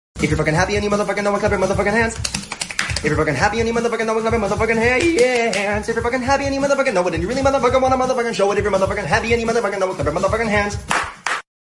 Clap Your Hand sound effects free download